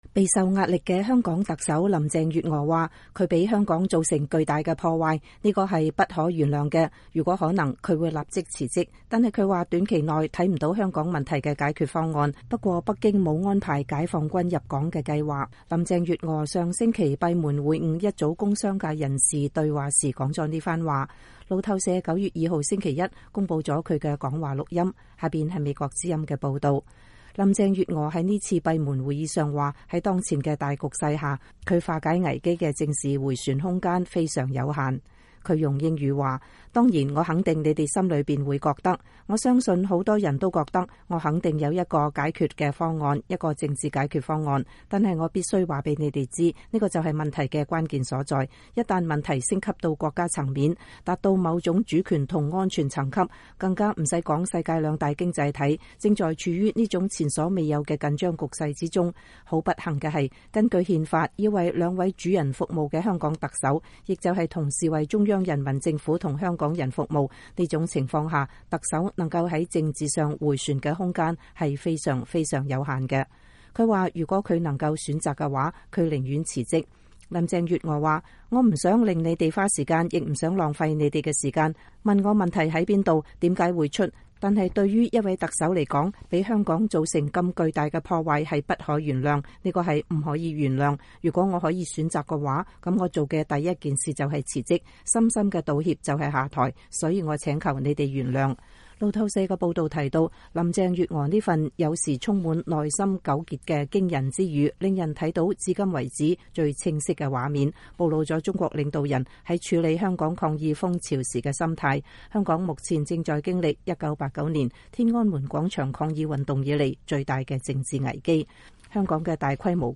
林鄭月娥上星期閉門會晤一組工商界人士時說了這番話。